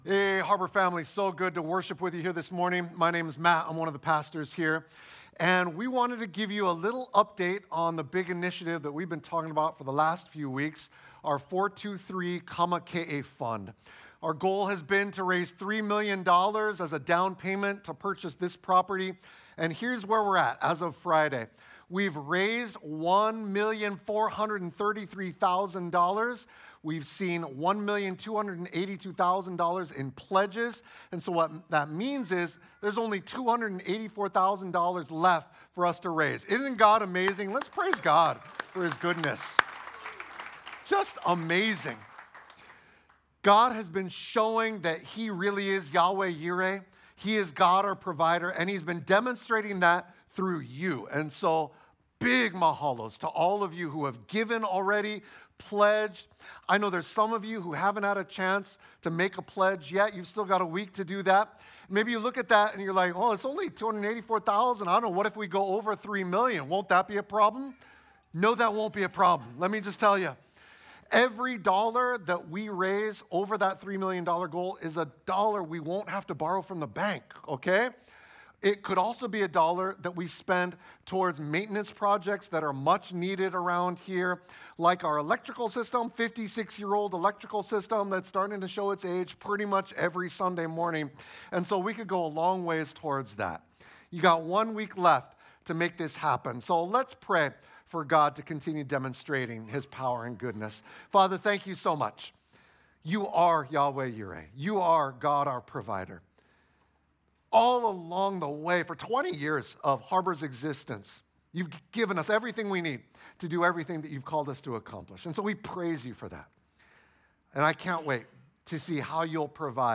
2026 Change Your Vigilance Preacher